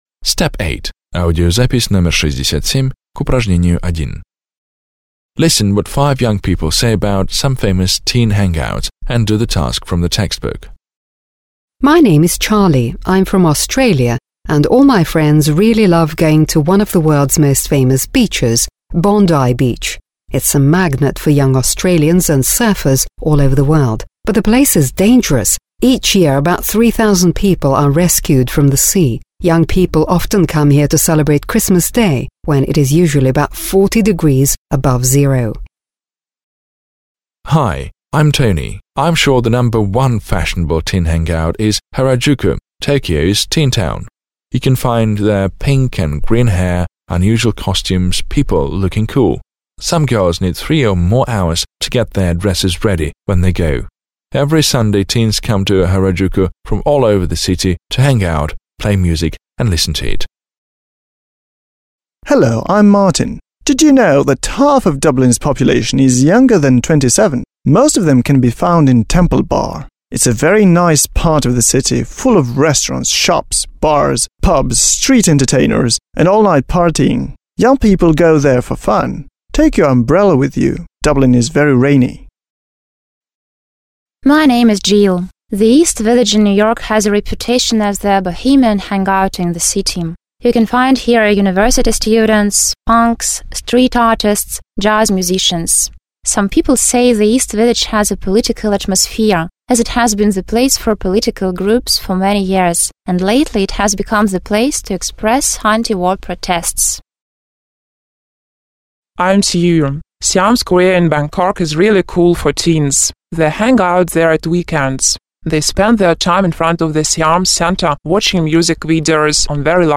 1. Вы услышите, что пять молодых людей говорят о самых известных в мире тусовках для подростков, (67).